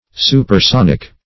supersonic \su`per*son"ic\, a.